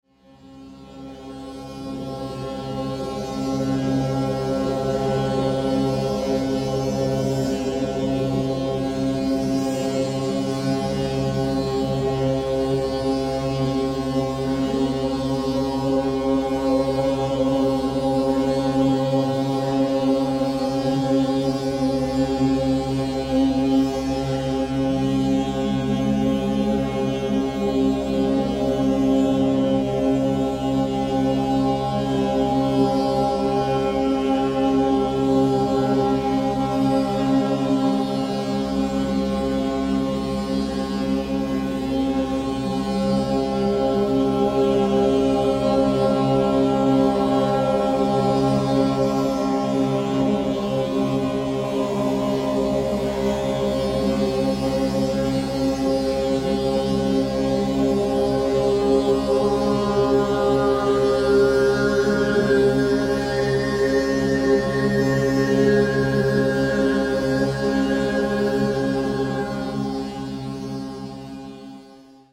Con musica strumentale e vocale per far volare l’anima.